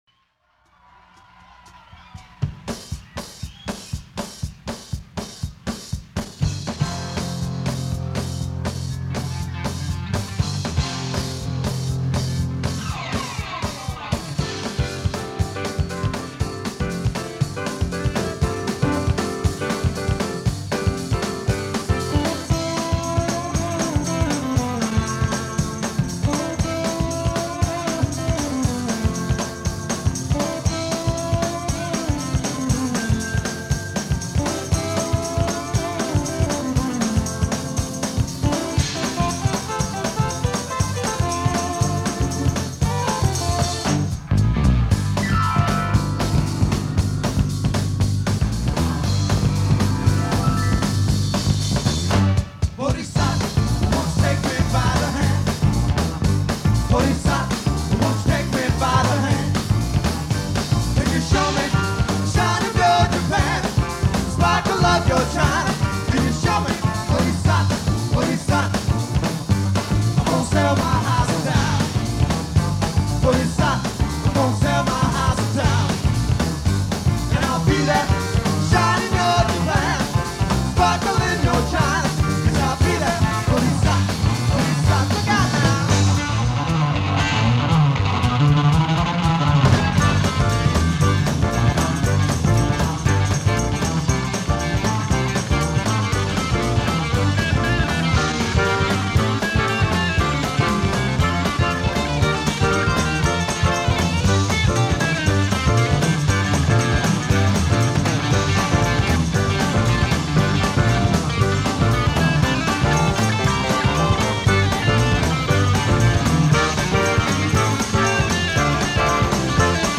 live at The Sopwith Camel — Glendale, Calif.